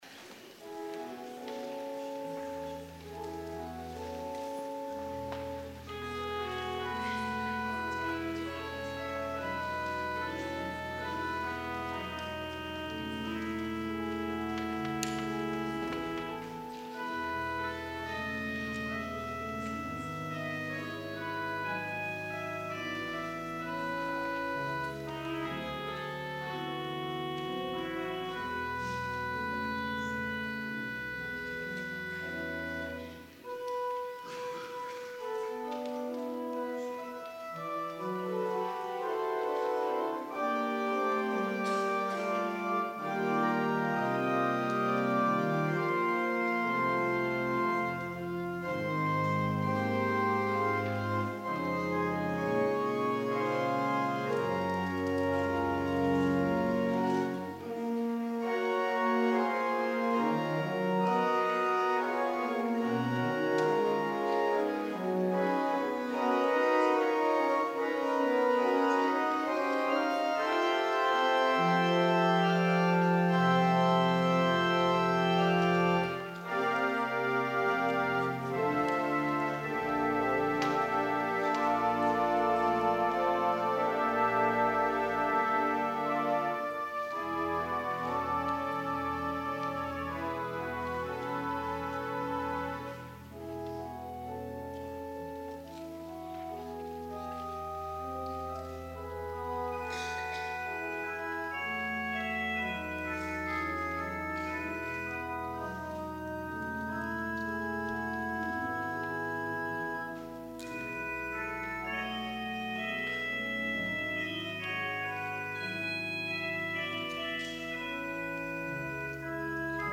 PRELUDE   Tribute (Lullaby for Organ) Craig Phillips, b. 1961
organ